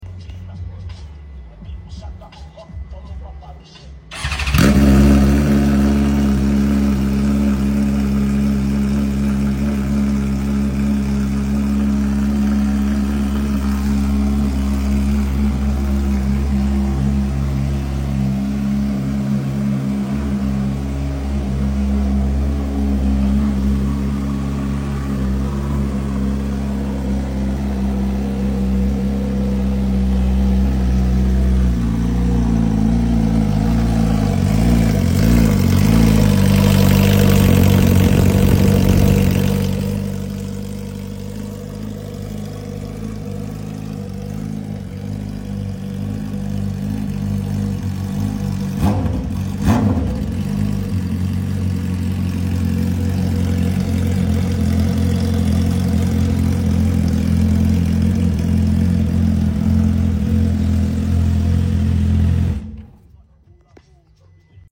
S1000 Rr Só O Cano Sound Effects Free Download